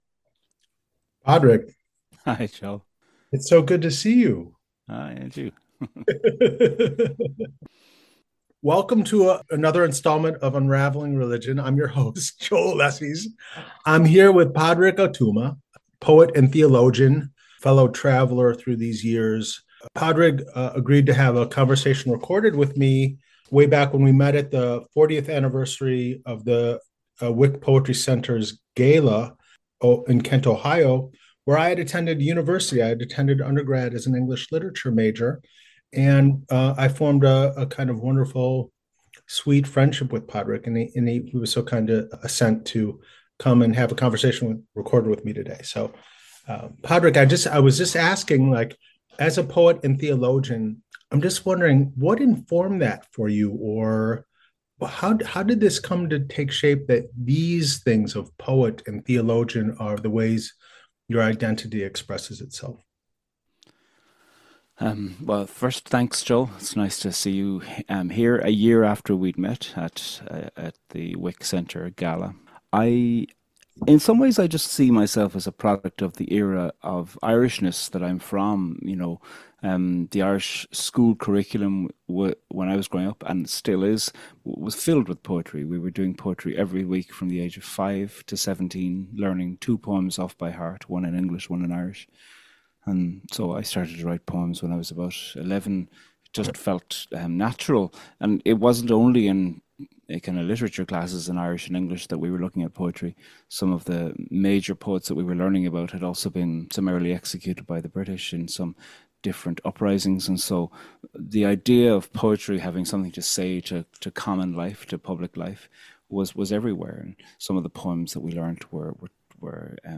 The Many Faces of Poetry, Language of The Heart, Song of Praise: A Conversation with Pádraig Ó Tuama, Poet and Theologian